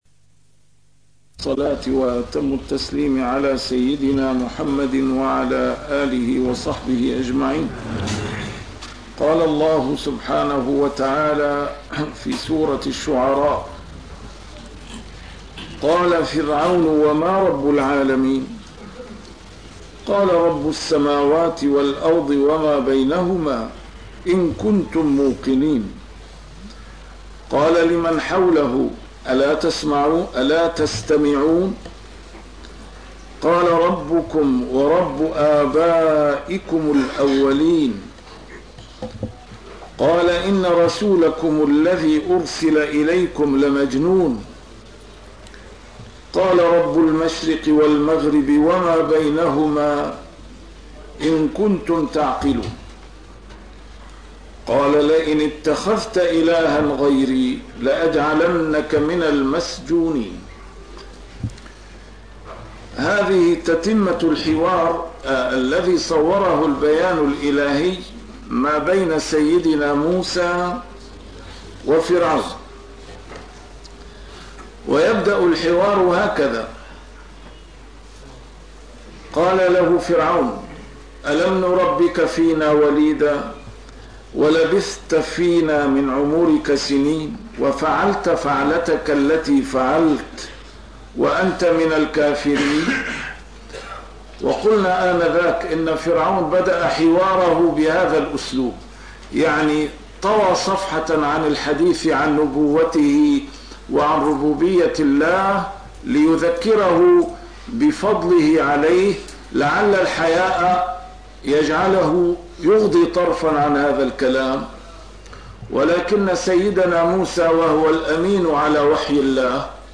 A MARTYR SCHOLAR: IMAM MUHAMMAD SAEED RAMADAN AL-BOUTI - الدروس العلمية - تفسير القرآن الكريم - تسجيل قديم - الدرس 226: الشعراء 023-029